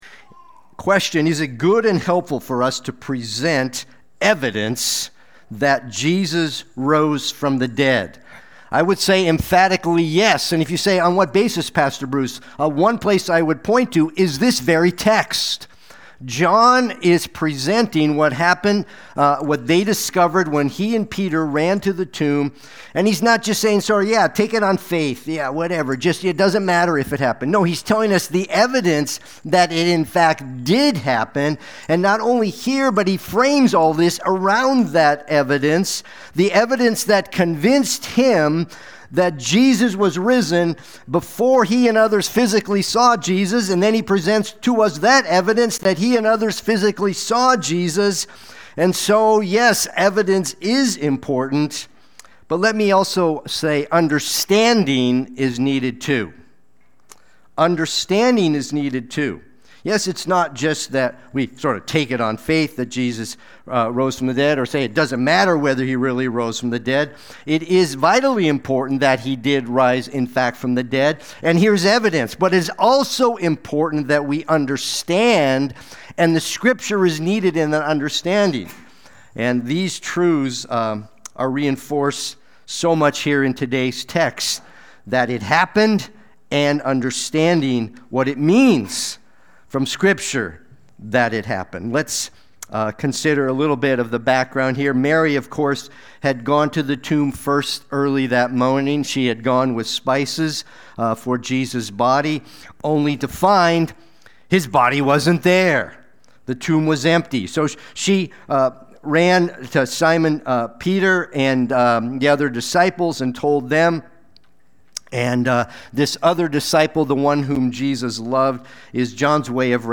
Watch the replay or listen to the sermon.
Sunday-Worship-main-3225.mp3